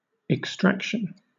Ääntäminen
Southern England
IPA : /ɪkˈstɹækʃən/